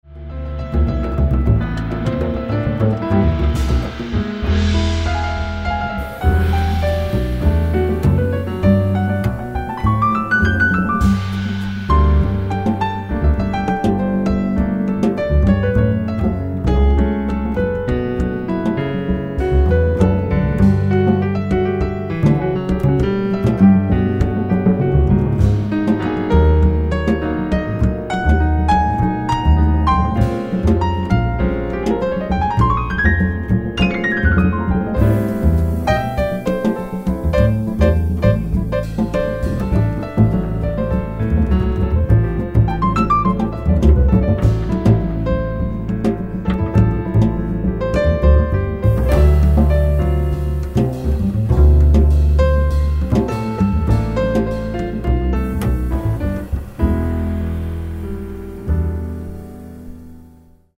drums
acoustic bass